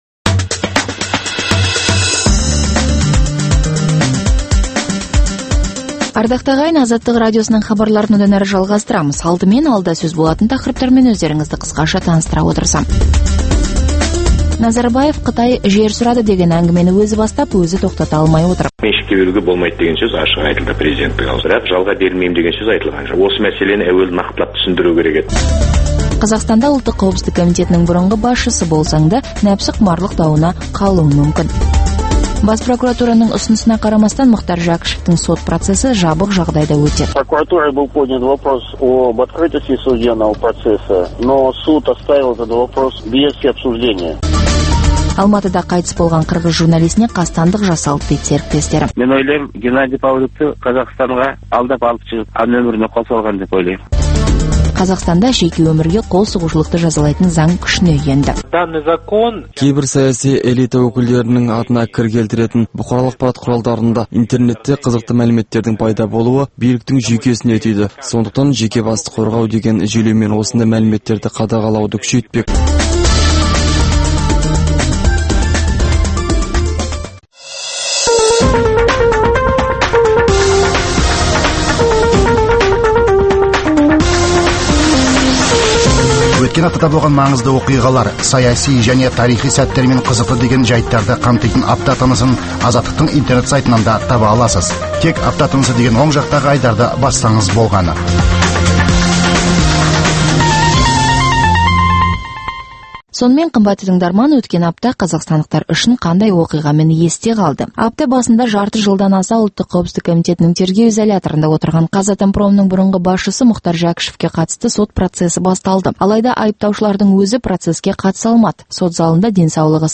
Апта ішінде орын алған елеулі оқиғалар мен өзгеріс, құбылыстар турасында сарапшылар талқылаулары, оқиға ортасынан алынған репортаждардан кейін түйіндеме, пікірталас, қазақстандық және халықаралық талдаушылар пікірі, экономикалық сараптамалар.